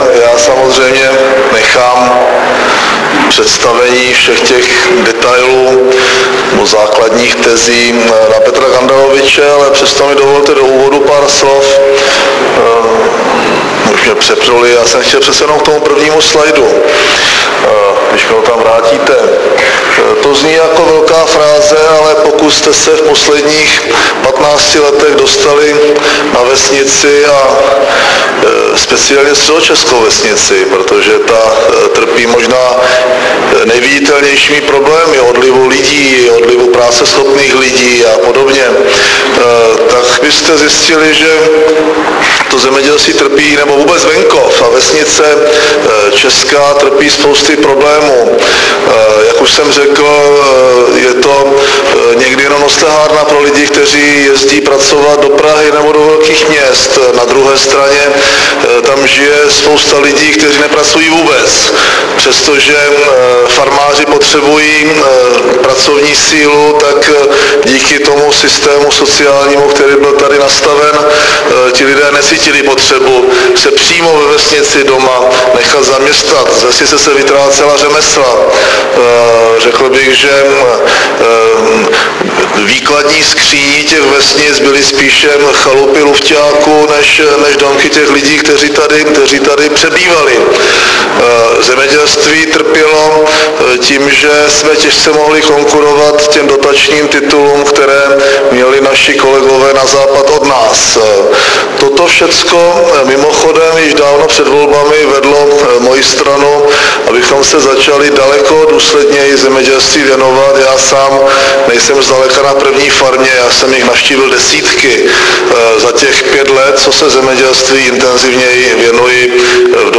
Vystoupení preméra Mirka Topolánka na tiskové konferenci k reformě zemědělské politiky 10.4.2008 v Radonicích